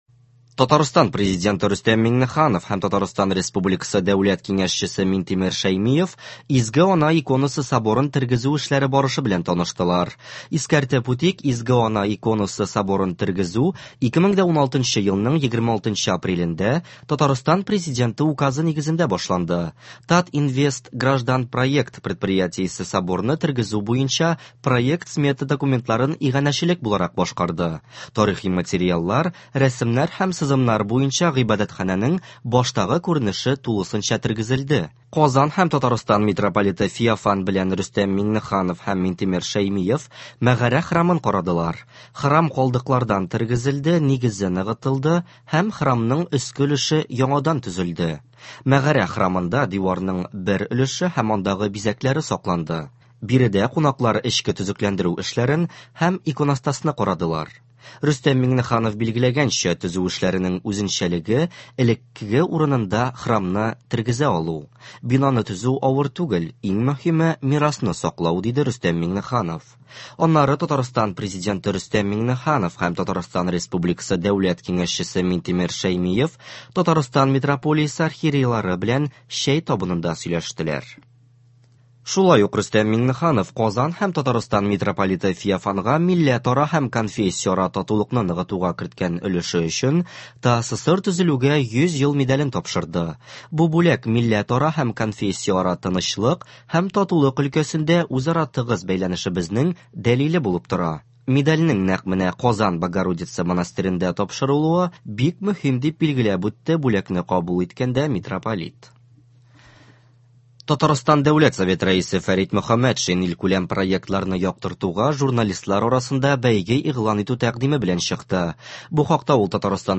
Яңалыклар. 14 гыйнвар.